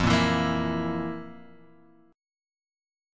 F Major 7th Suspended 4th
FM7sus4 chord {1 1 2 3 x 0} chord